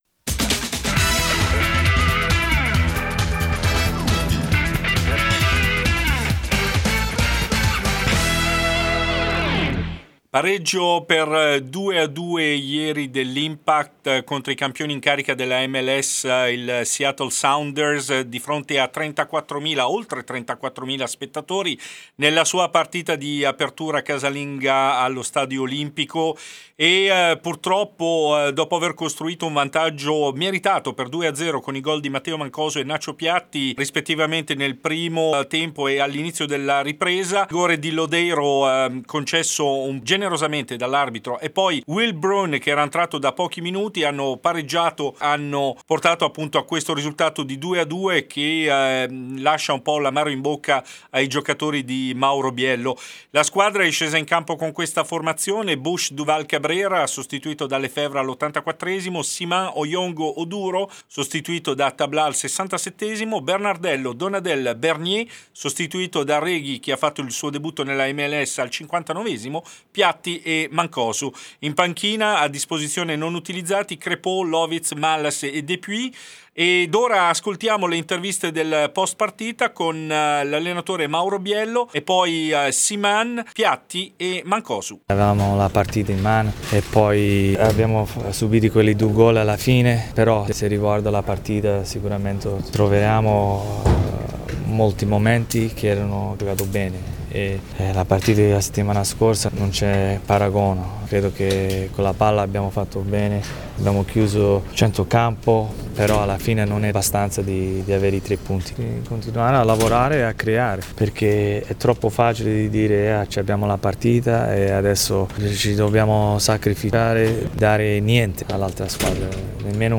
Servizio completo con le interviste post-partita